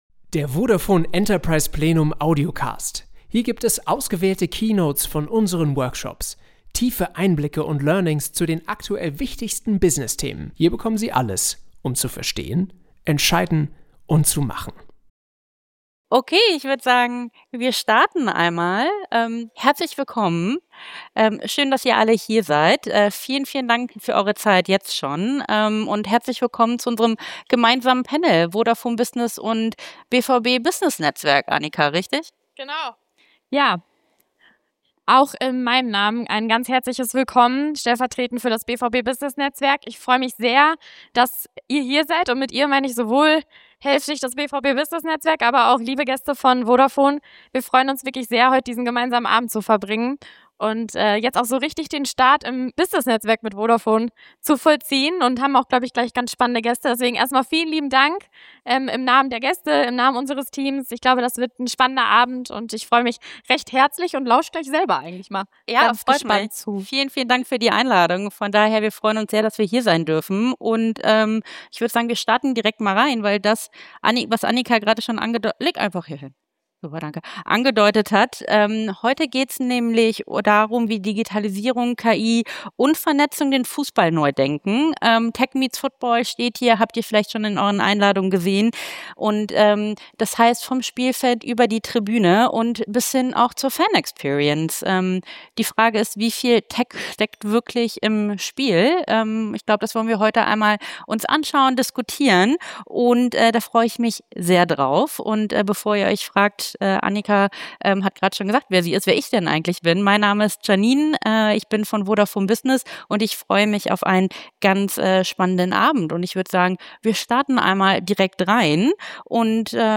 Panel Talk Tech meets Football Gemeinsam werfen wir einen Blick darauf, wie technologische Innovationen das Fußball-Business verändern – und welche Impulse sich daraus auch auf Ihr eigenes Business übertragen lassen